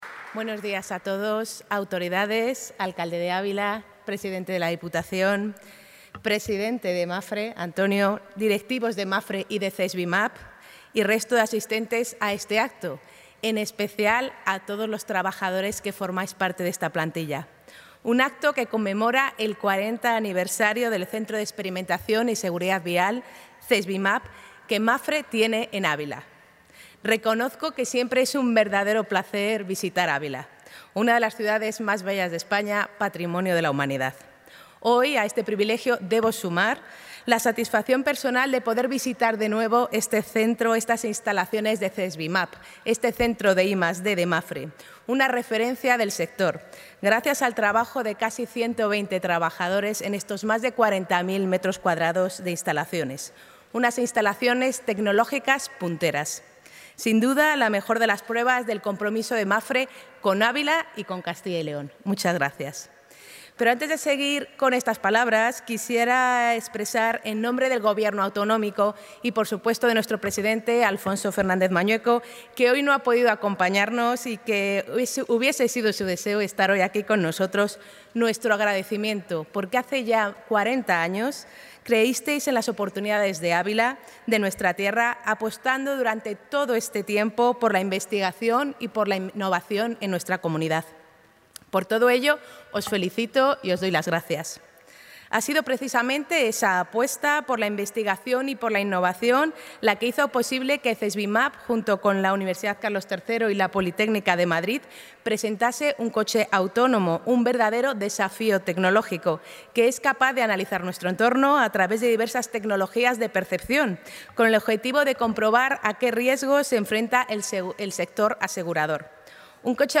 Intervención de la consejera.
La consejera de Movilidad y Transformación Digital, María González Corral, ha visitado hoy en Ávila las instalaciones del Centro de Experimentación y Seguridad Vial de Mapfre (CESVIMAP), con motivo de su 40 aniversario.